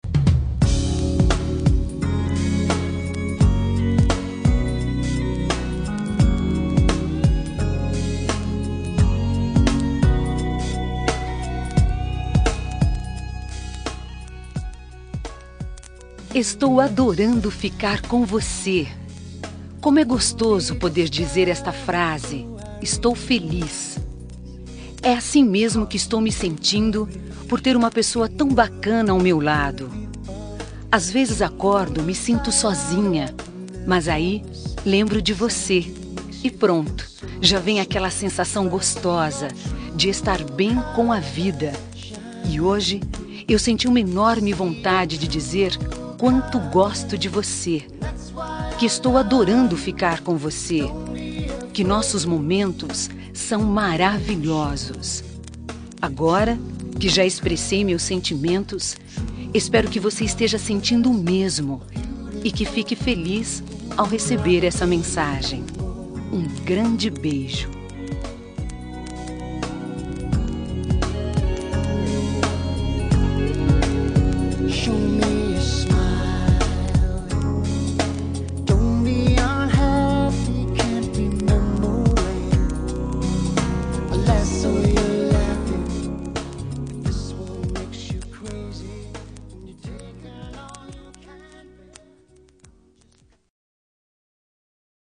Telemensagem Momentos Especiais – Voz Feminina – Cód: 201883- Adorando Ficar com Você